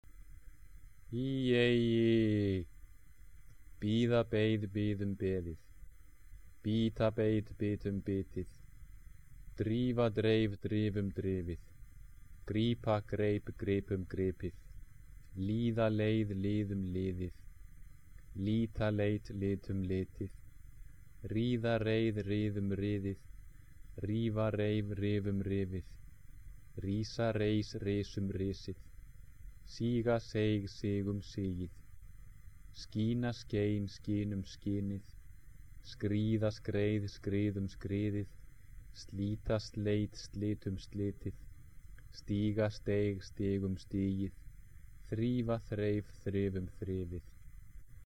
hljómfall - rhythm